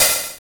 Wu-RZA-Hat 53.wav